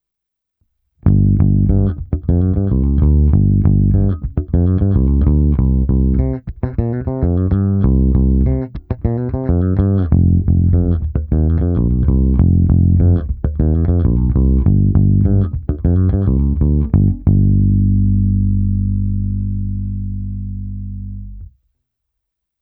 V řetězci dále byl aktivní preamp Darkglass Harmonic Booster, kompresor TC Ellectronic SpectraComp a preamp Darkglass Microtubes X Ultra se zapnutou simulací aparátu.
Cívky 2 a 3 - zvuk ala Music Man - basy +30, středy +30, výšky -30